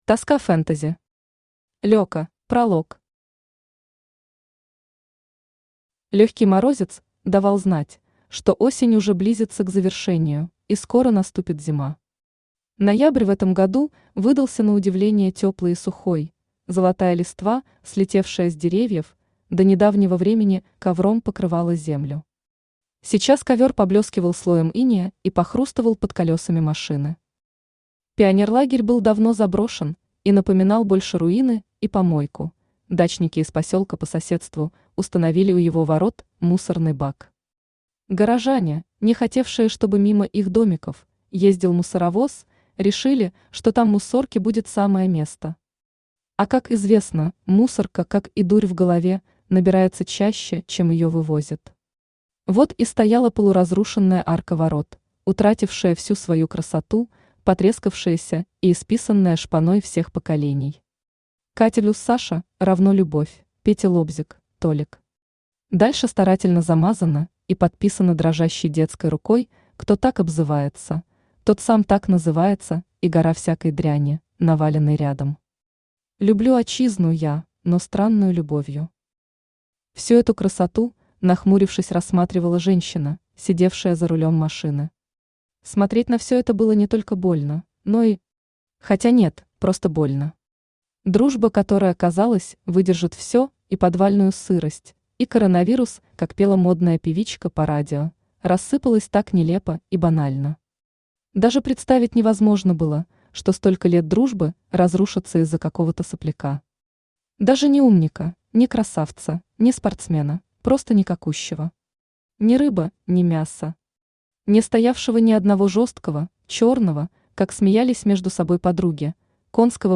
Aудиокнига ЛёКа Автор Тоска Фэнтези Читает аудиокнигу Авточтец ЛитРес.